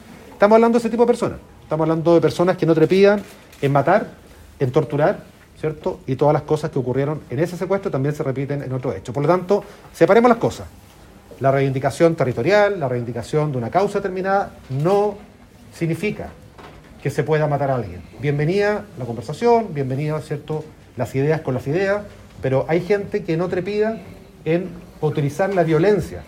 Durante esta mañana, el Ministro del Interior, Rodrigo Delgado, se refirió a la detención de estas personas, señalando que, las reivindicaciones territoriales se consiguen a través del dialogo, no asesinando personas.